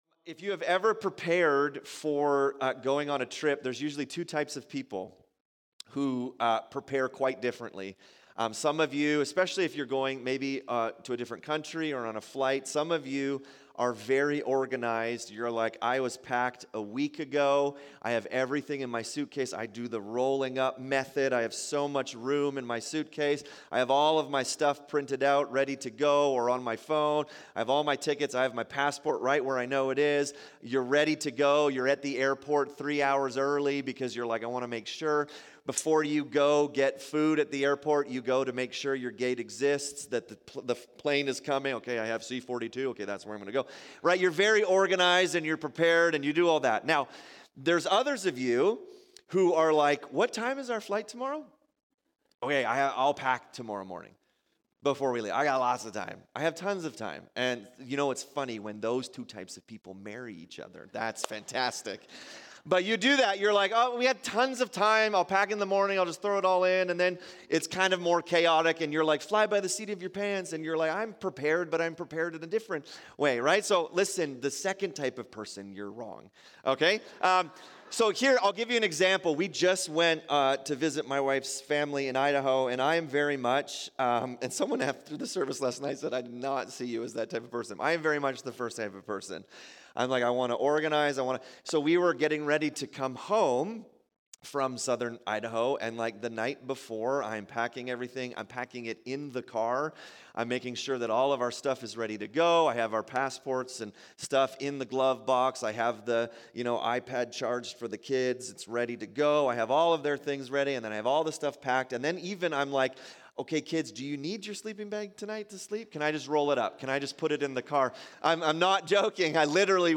In this sermon we look at the parable of the 10 virgins. What does it look like to be ready for the return of Jesus?